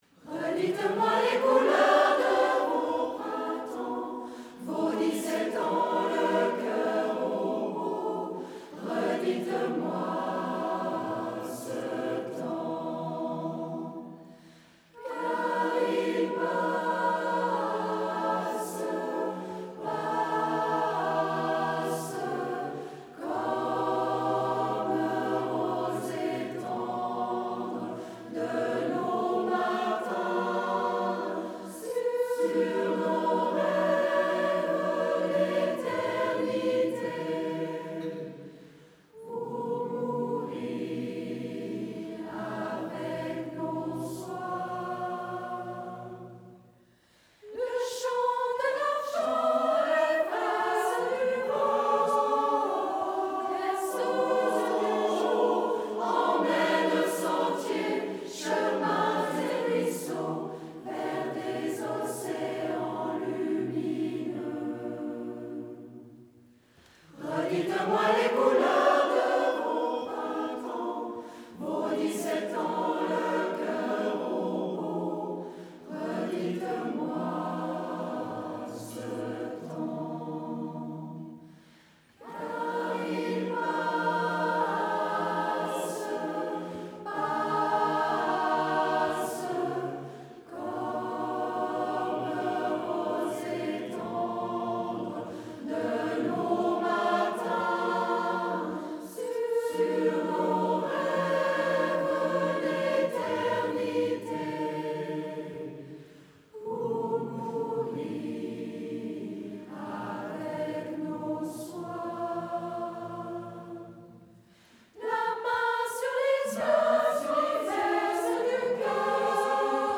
Redites moi ce temps Echallens2017.mp3